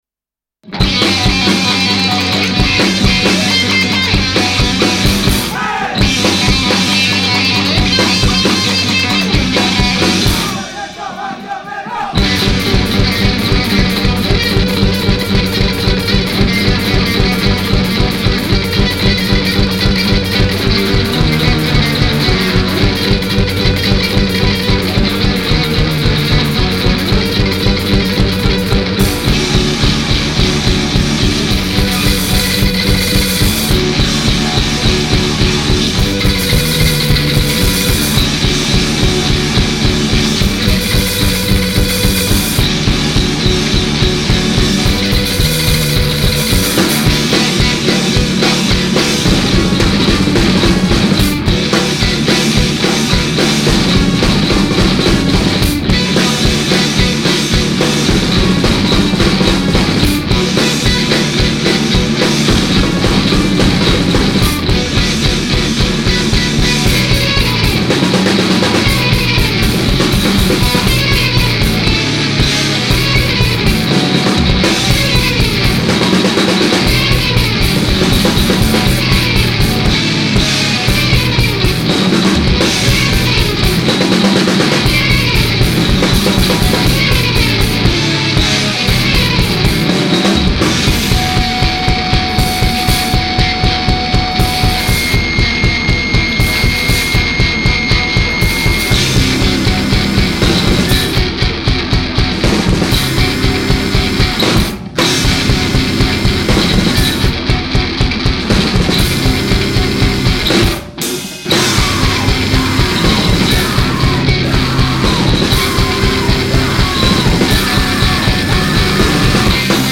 genre: zumpa-noise 7 "vinyl